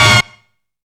CHORD STAB.wav